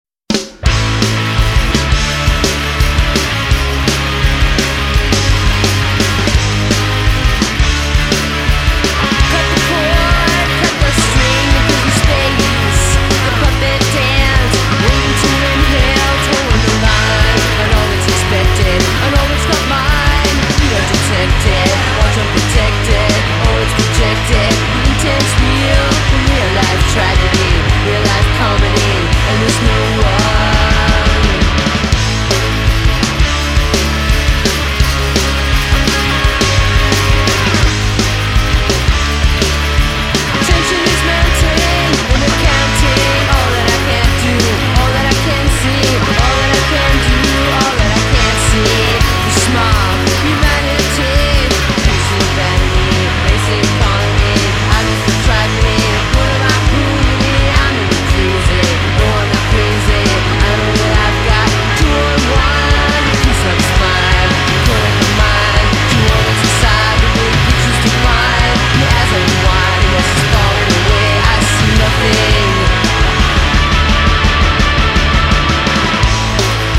aggro-pop intensity